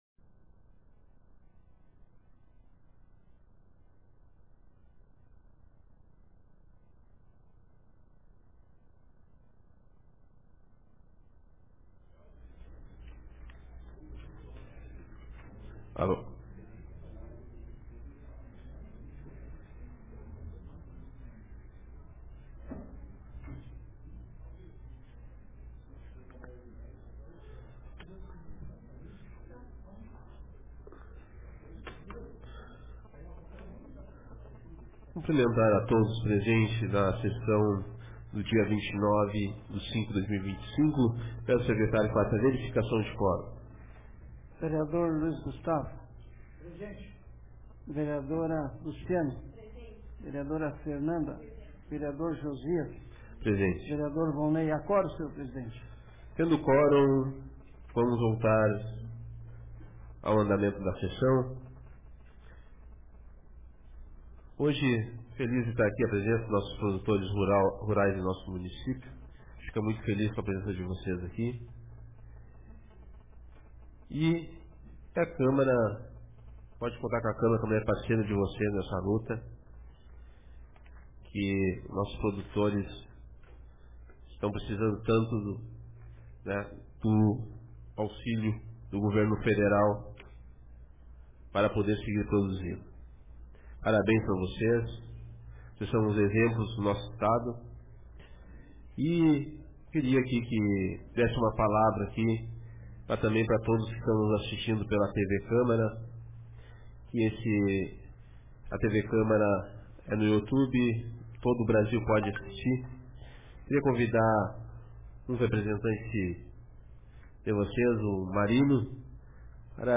Sessão Ordinária da Câmara de Vereadores de Hulha Negra Data: 29 de maio de 2025